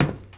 Footstep_Metal 03.wav